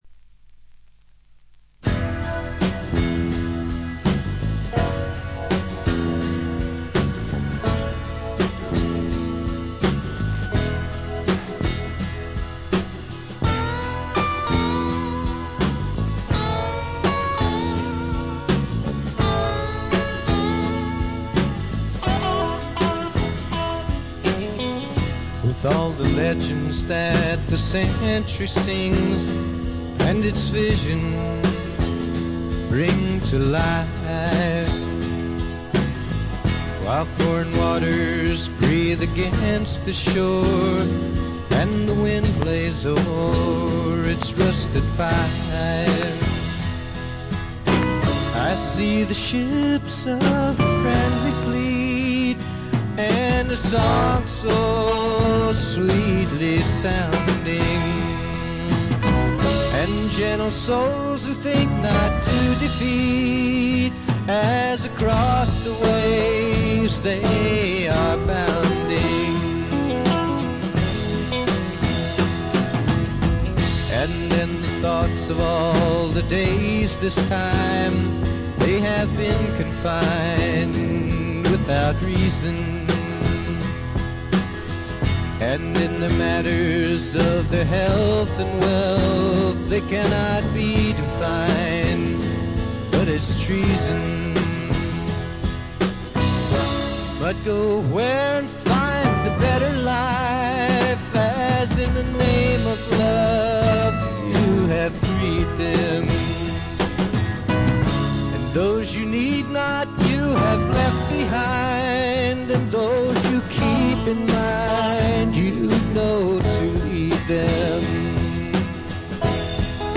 これ以外は軽微なバックグラウンドノイズにチリプチ少々。
試聴曲は現品からの取り込み音源です。
Recorded at The Village Recorder